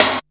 Index of /90_sSampleCDs/USB Soundscan vol.20 - Fresh Disco House I [AKAI] 1CD/Partition C/09-SNARES